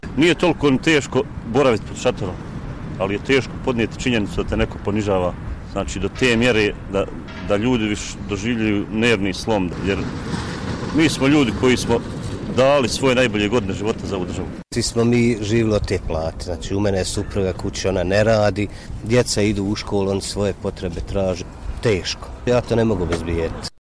Ovo su samo neke tada zabilježene izjave ogorčenih učesnika protesta:
Mišljenja vojnika